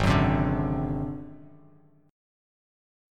G#7b5 chord